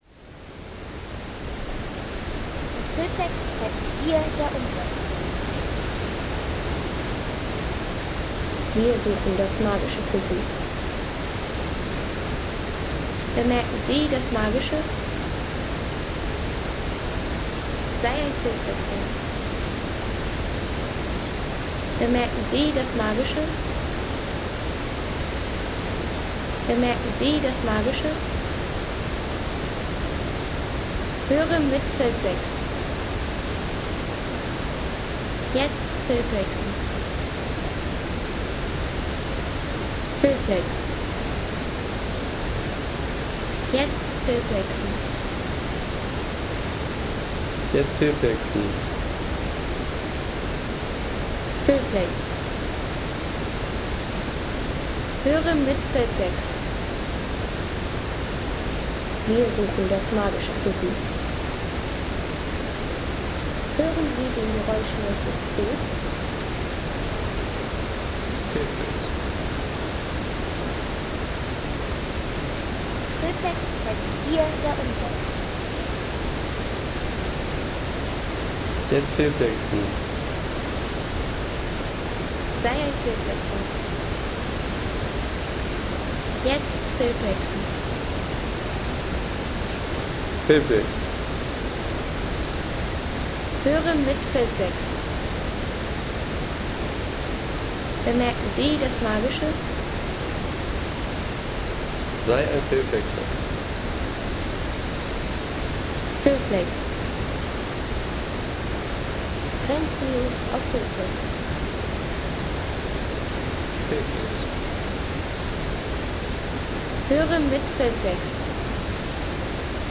Erholungsgenuss für alle, wie z.B. diese Aufnahme: Schlatenbach-Wasserfall, 1960m
Schlatenbach-Wasserfall, 1960m